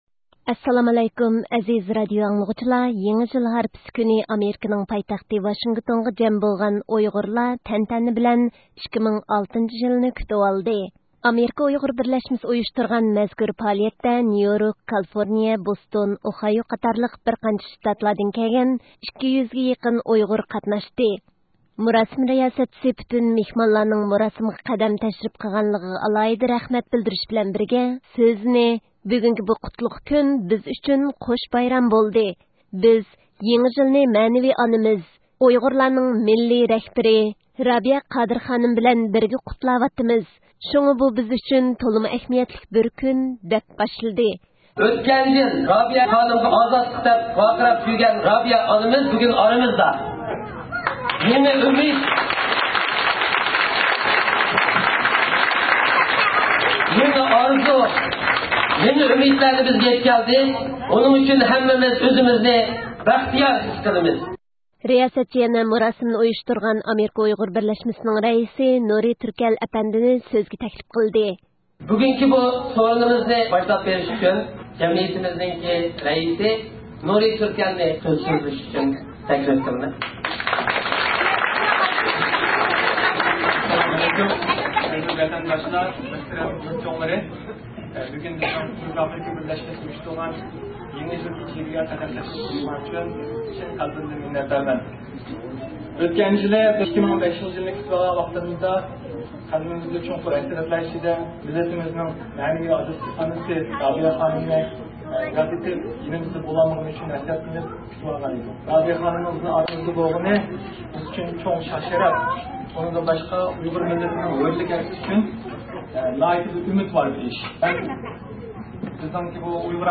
يېڭى يىل ھارپىسى كۈنى ئامېرىكىنىڭ پايتەختى ۋاشىنگتونغا جەم بولغان ئۇيغۇرلار تەنتەنە بىلەن 2006 - يىلنى كۈتۈۋالدى.
رابىيە قادىر خانىم يېڭى يىل پائالىيىتىدە سۆز قىلماقتا